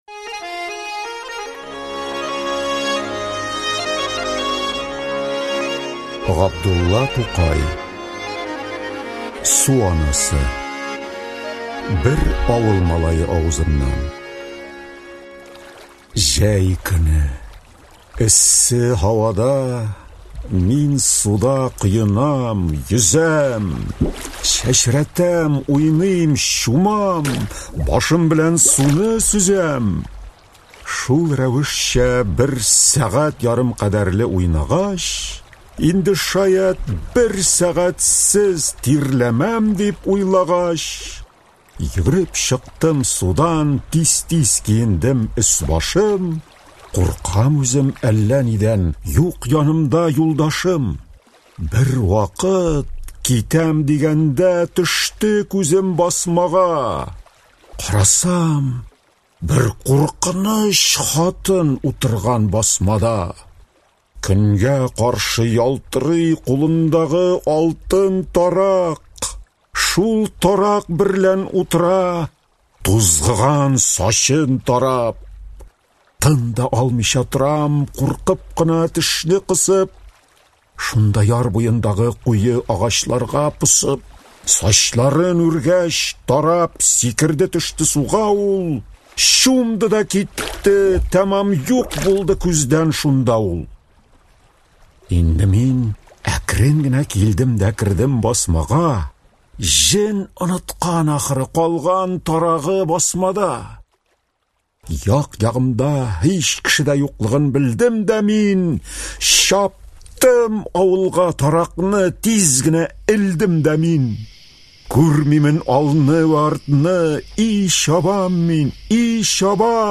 Aудиокнига Су анасы Автор Тукай Габдулла.